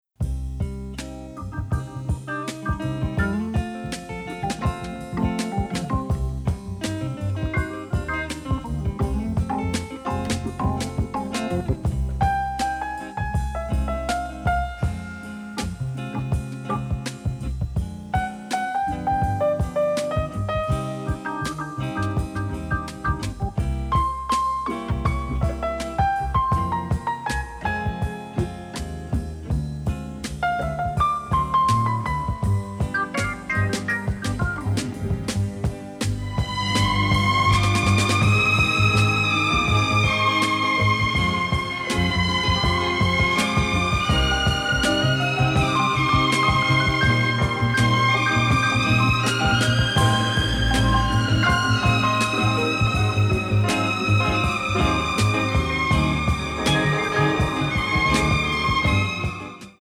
psychedelic cult classics